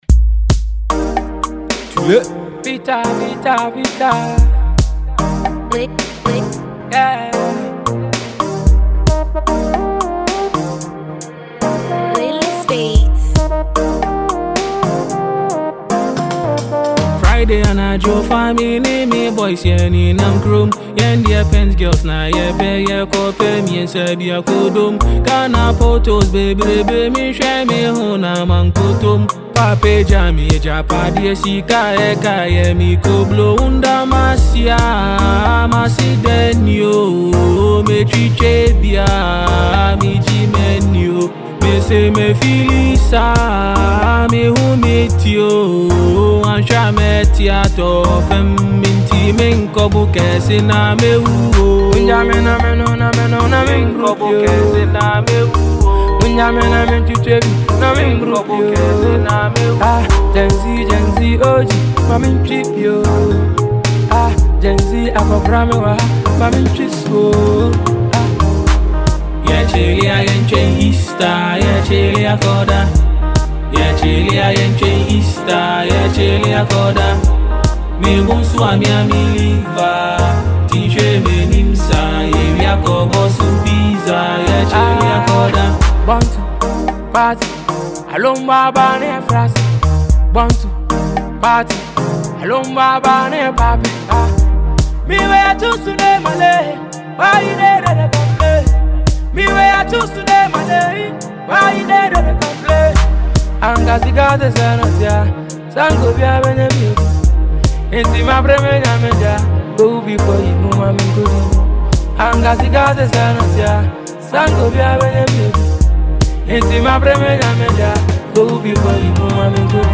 Simple, catchy, and straight fire.